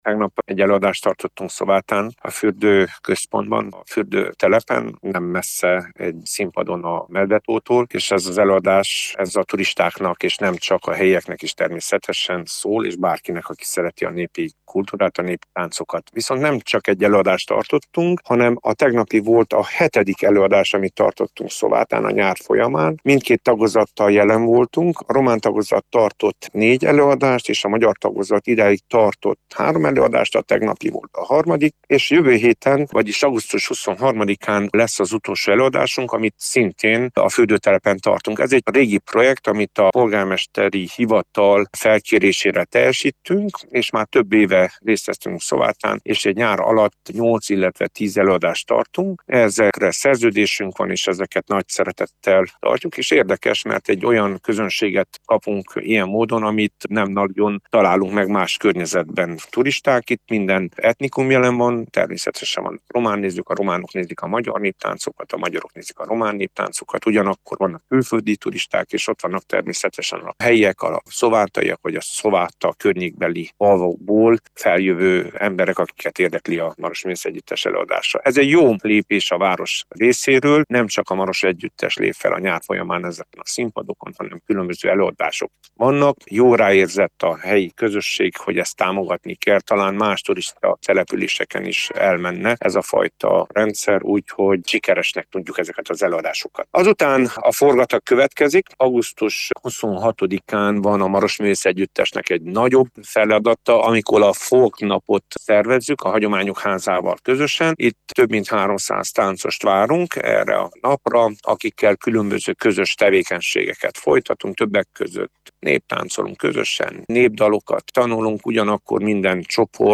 Egy üdülővárosban is hasznosak az ilyen jellegű, kulturális, események, hiszen az oda látogatók kapnak egy pluszt a nyaralás mellett, ami még kedveltebbé teheti az adott települést és nem kizárt, hogy a jövőben pont ezért fognak ismét ellátogatni oda az ember, vélekedett